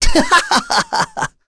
Ezekiel-vox-Happy2.wav